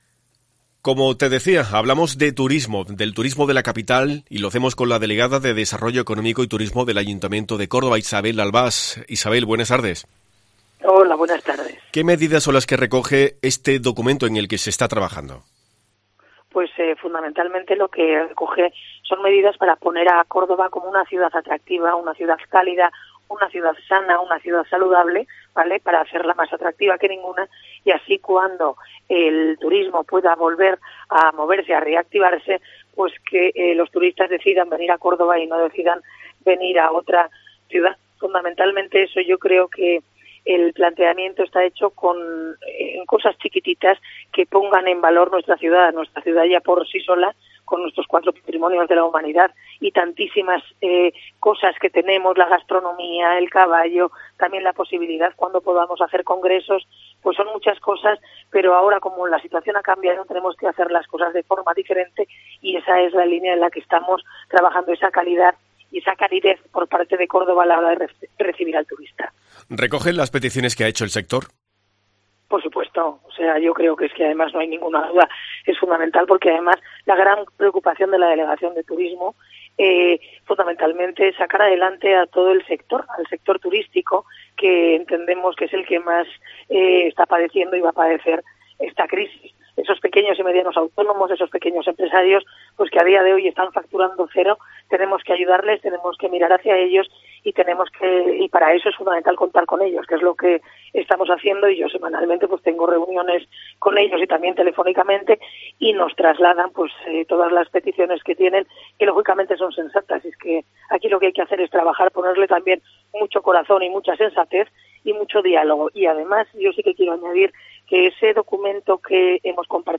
La primera teniente de alcalde, Isabel Albás, ha explicado en COPE que el Ayuntamiento de Córdoba adoptará medidas “para poner a Córdoba como una ciudad atractiva, cálida y sana para que cuando el turismo pueda volver a reactivarse los turistas se decidan por nuestra ciudad que ya por sí sola con nuestros cuatro patrimonios de la Humanidad y muchas otras cosas es ya atractiva”.